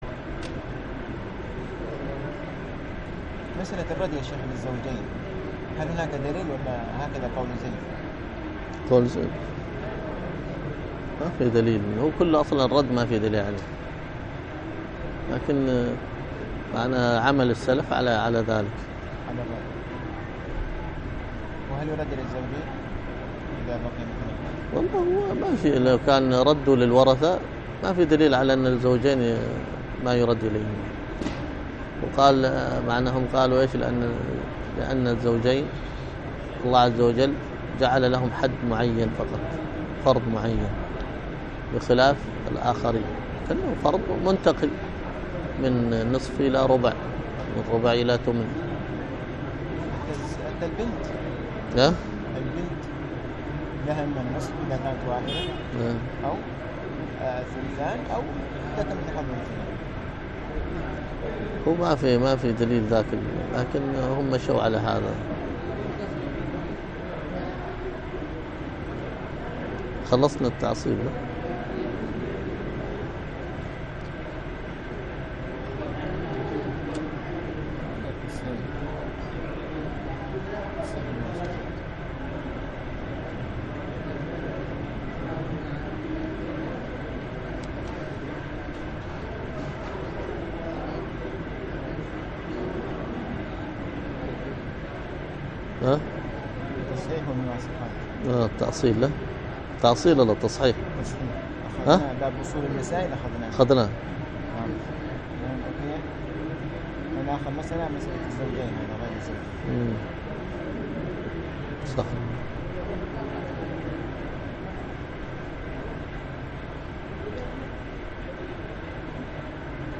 الدرس في كتاب البيع 12